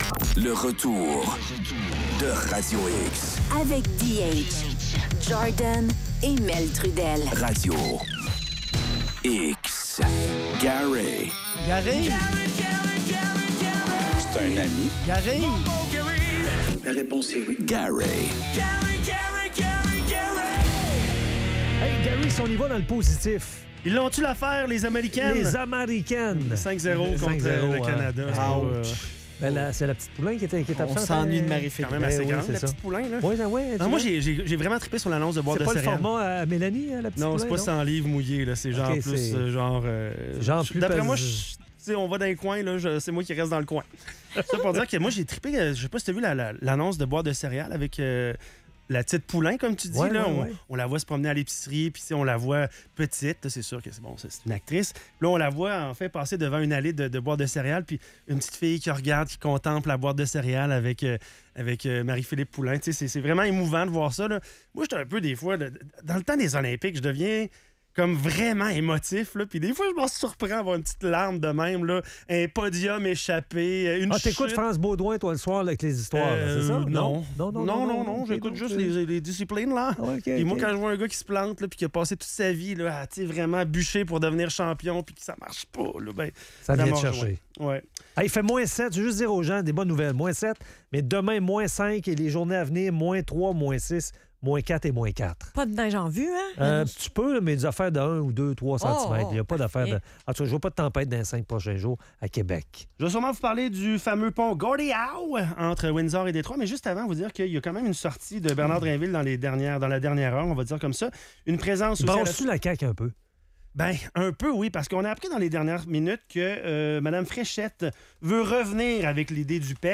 JF Roberge se retrouve isolé dans le débat sur l'immigration, alors que Christine Fréchette propose de réactiver le programme Expérience Québec (PEQ), aboli en novembre dernier. Les animateurs analysent la situation chaotique du gouvernement de la CAQ et évoquent les tensions internes, notamment autour de la chefferie et des décisions controversées.